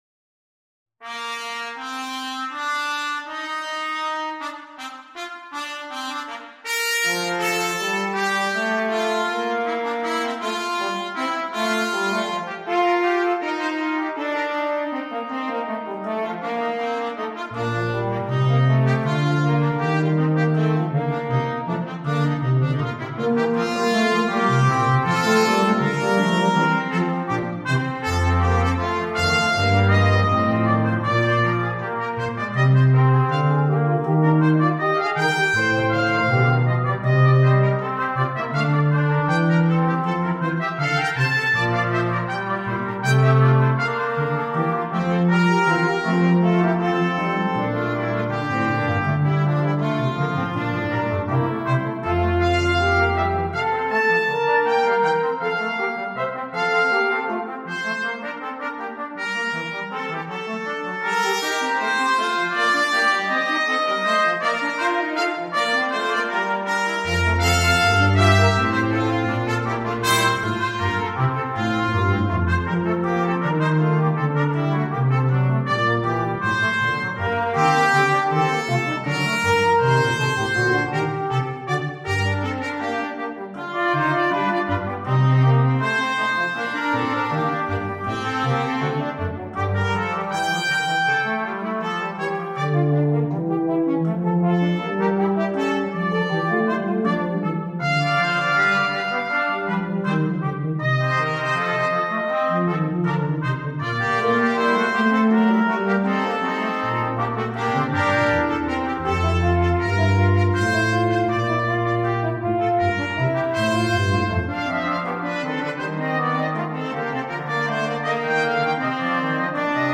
without solo instrument
Classical
Part 1: Bb Trumpet, Bb Cornet
Part 3: F Horn
Part 4: Trombone – Bass clef
Part 5: Tuba